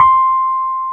Index of /90_sSampleCDs/Roland LCDP10 Keys of the 60s and 70s 2/PNO_Rhodes/PNO_73 Suitcase
PNO C5 F  0E.wav